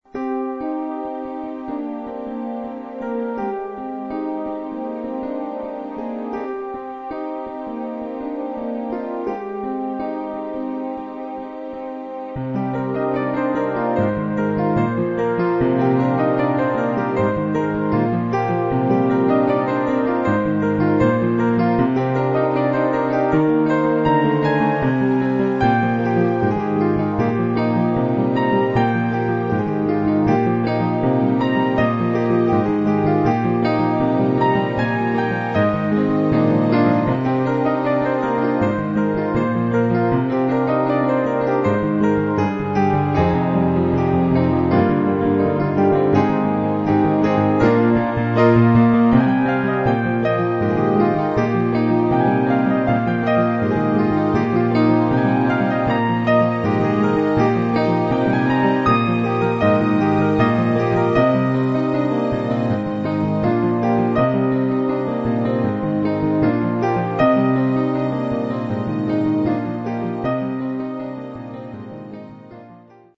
More cheerful junk.
This one sounds like something that was rejected from an elevator soundtrack. Digital electric piano plus strings equals more cheese than a large order of nachos. The ending is nice, though.